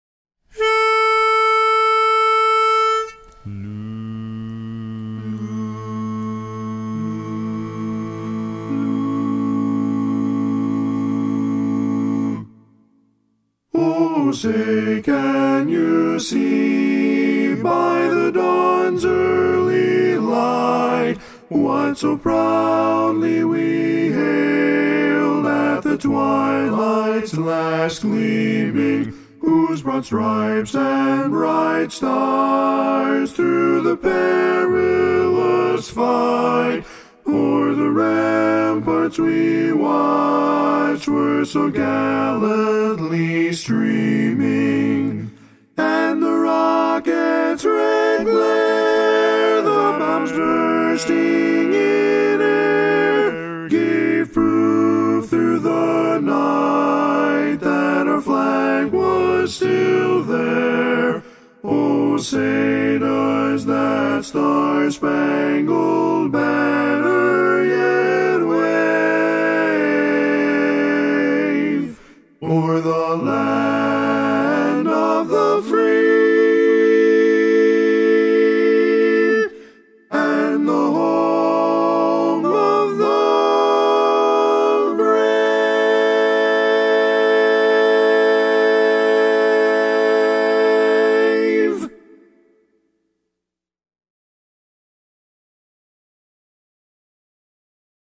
Up-tempo
Barbershop
A Major
Tenor